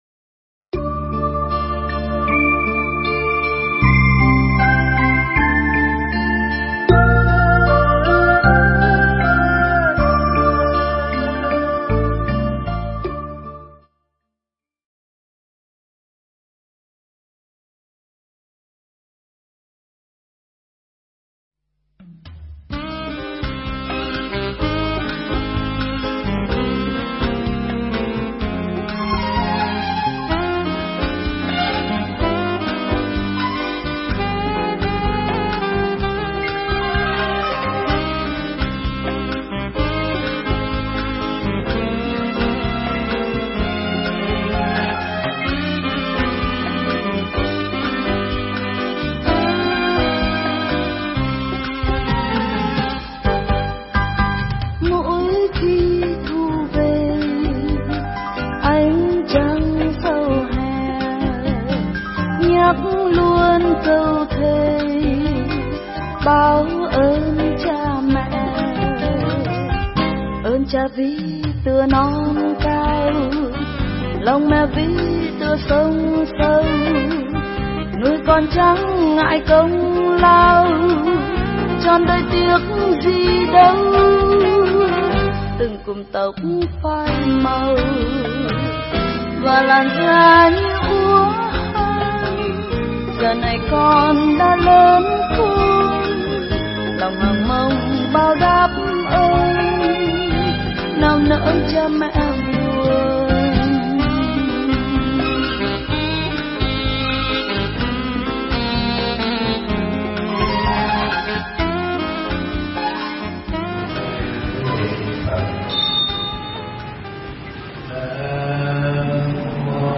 Mp3 Thuyết pháp Tứ Ân